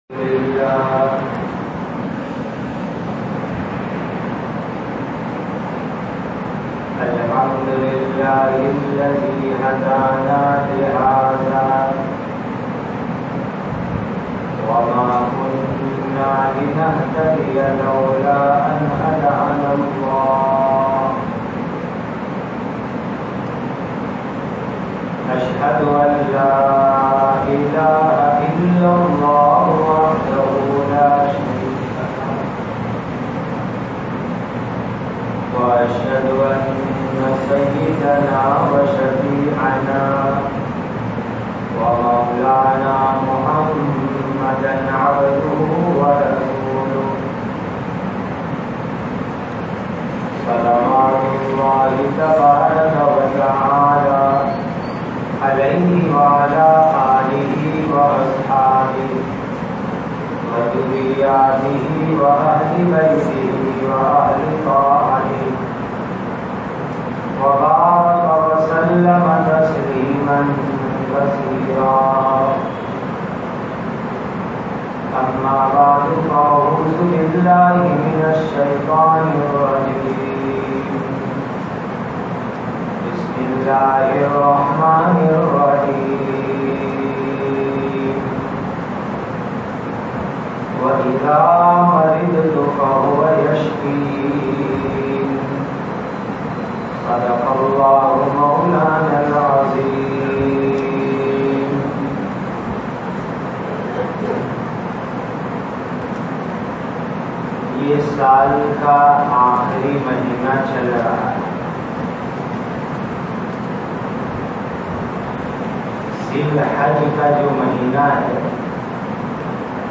Imaanwalo Ko Sabse Zyada Mohabbat Allah Se Hoti He, Jumma Bayan, Salabatpura Badi Masjid, Surat Explained by